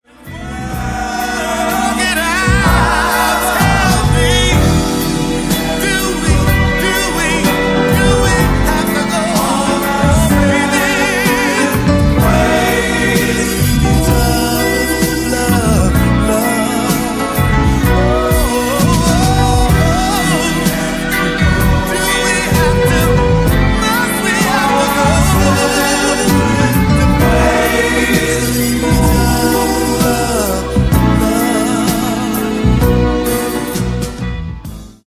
Genere:   Disco | Funky | Soul